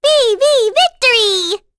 Luna-Vox_Victory_b.wav